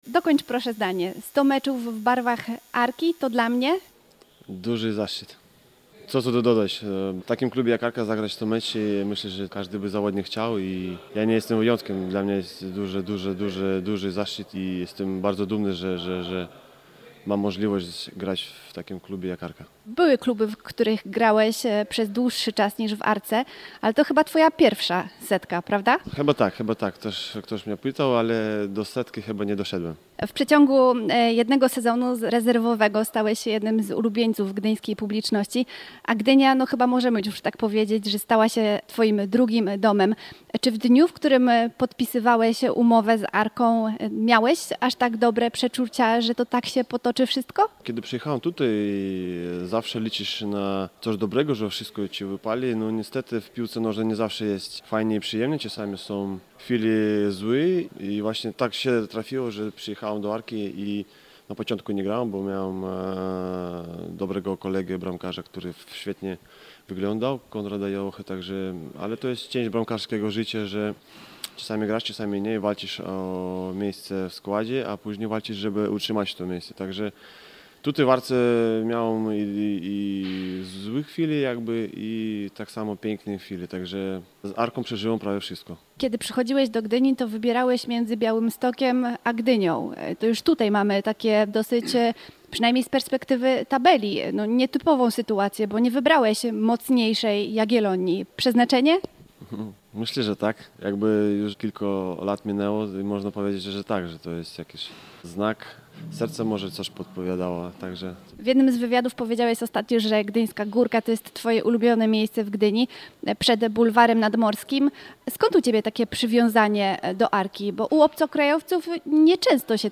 Posłuchaj rozmowy z Pavelsem Steinborsem: